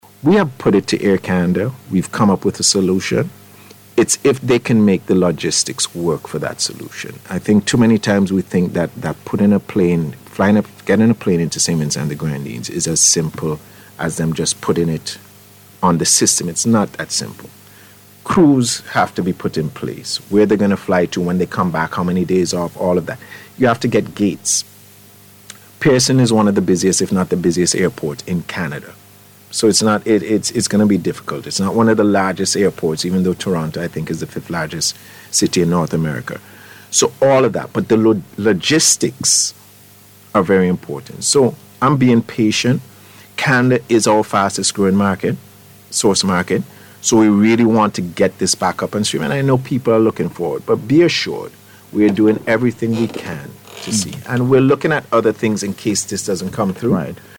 spoke on the issue, during NBC’s Talk Yuh Talk programme this morning.